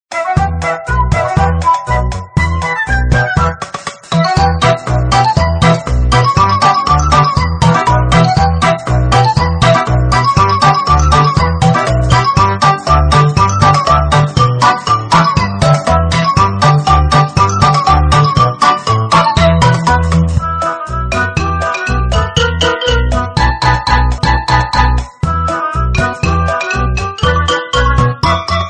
– Thể loại: “Trò Chơi”